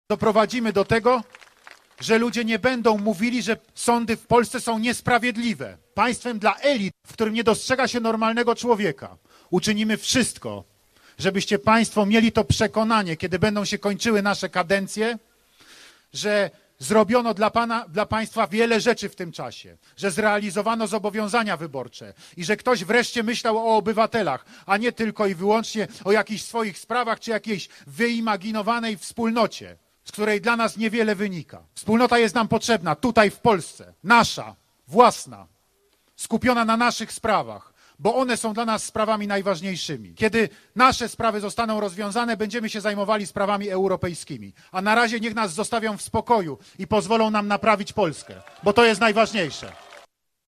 Prezydent Andrzej Duda w miniony wtorek gościł w Leżajsku, gdzie wygłosił przemówienie, które wstrząsneło polską klasą polityczną.